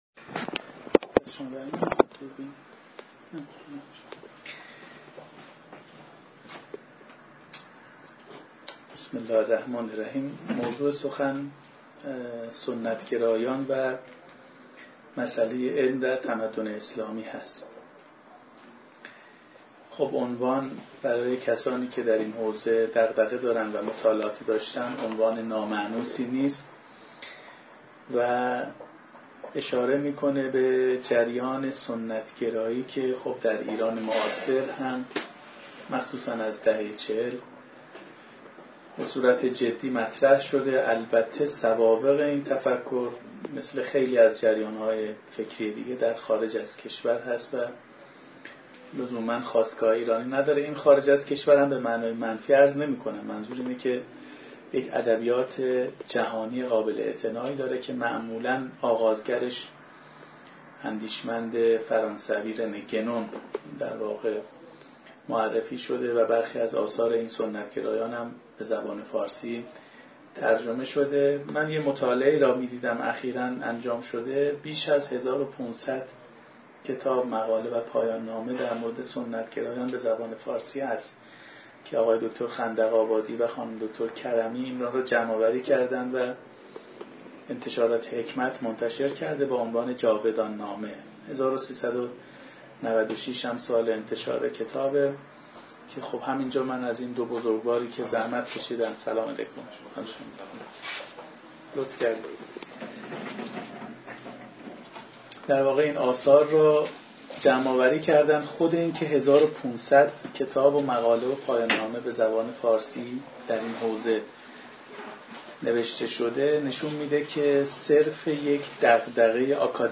محل برگزاری: طبقه دوم، سالن ادب